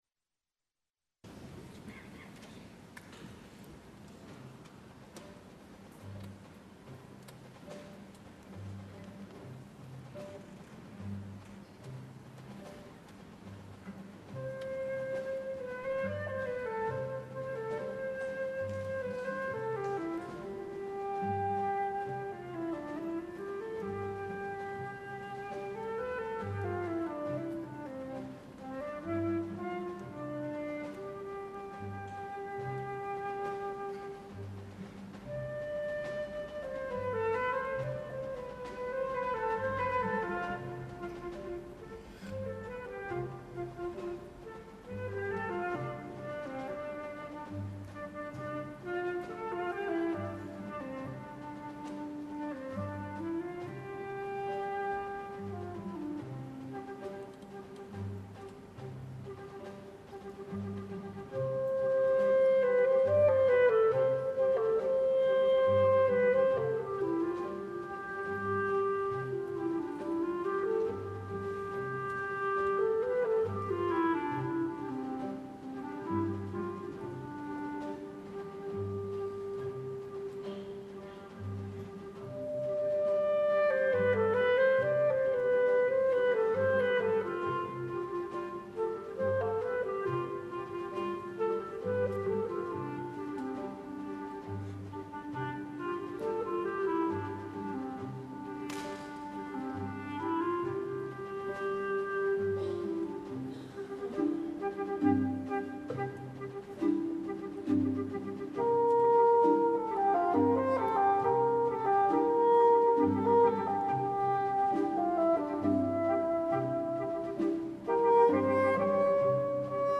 Group: Dance A 3/4 dance that originated in Spain in the late 18th century, a combination of the contradanza and the sevillana.